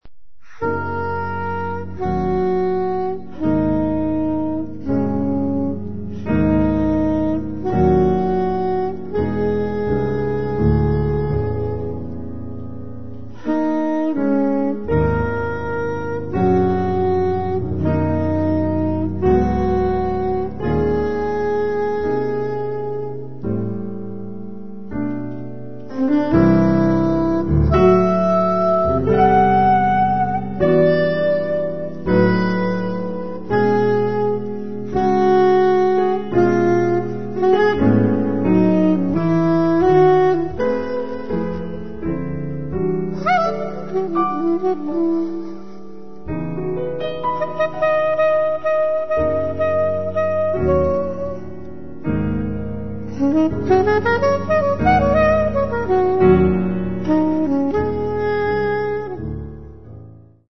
pianoforte
sassofoni